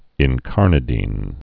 in·car·na·dine
(ĭn-kärnə-dīn, -dēn, -dĭn)